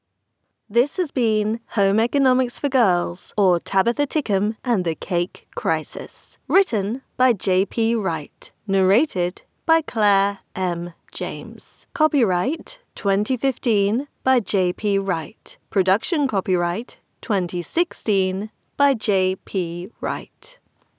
Boost overall volume, very gentle compression (to increase loudness) and trim volume a bit.
So we officially have a studio recording and from here it’s fine tuning, maybe lowering the background noise a bit (maybe tiny noise reduction, Steve’s LF-Rolloff), polishing vocal tone for more pleasant timbre (DeEssing, Equalizer, Low Pass filter).